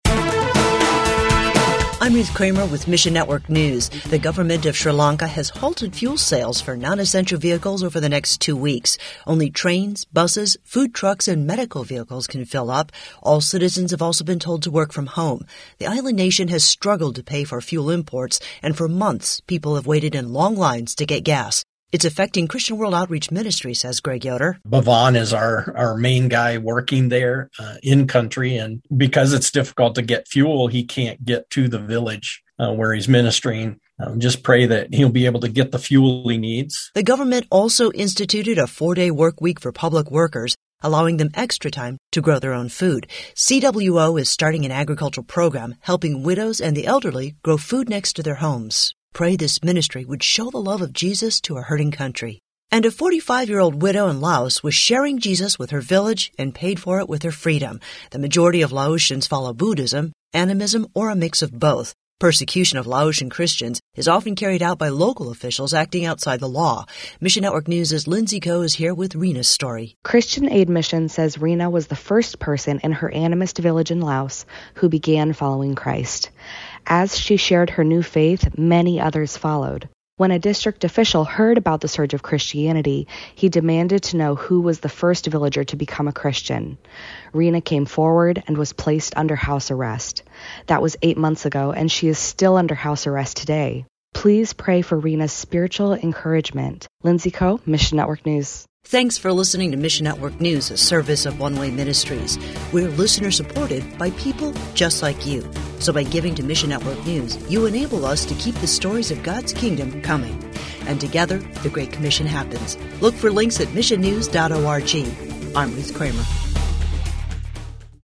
Audio Broadcast